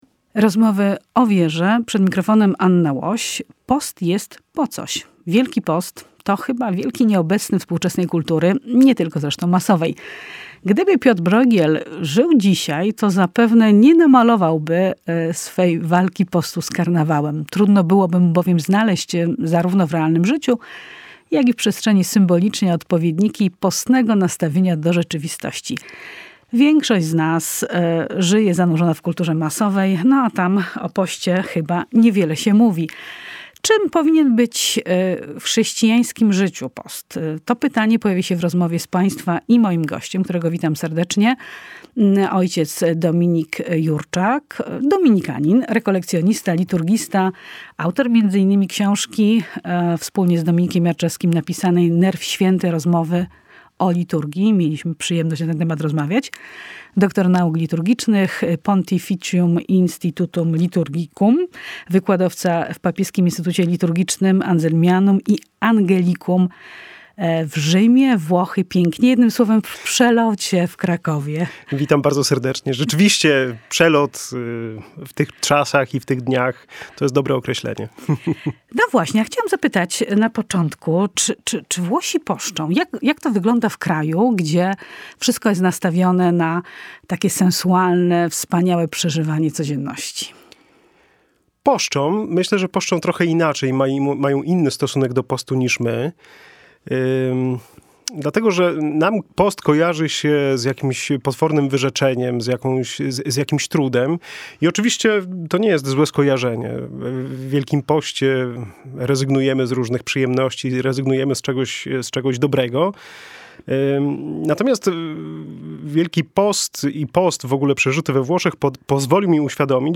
Audycja została wyemitowana na antenie Radia Kraków w niedzielę 6 marca 2022, rozmowę prowadziła red.